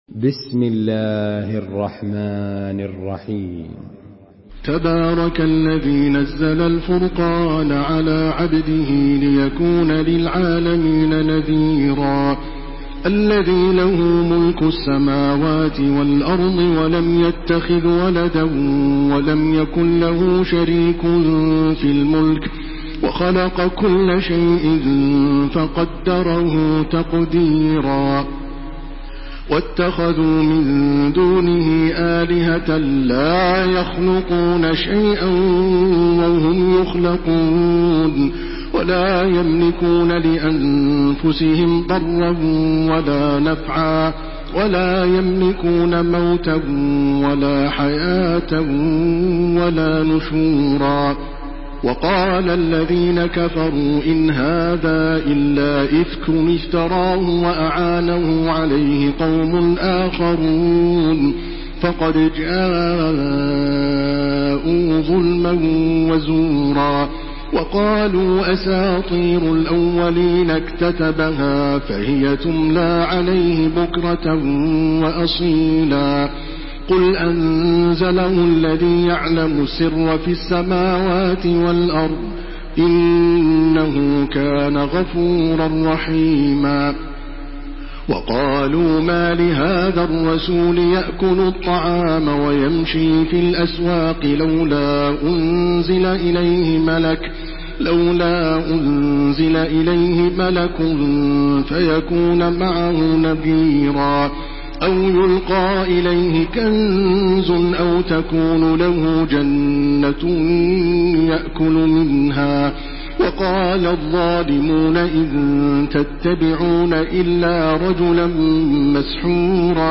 تحميل سورة الفرقان بصوت تراويح الحرم المكي 1429
مرتل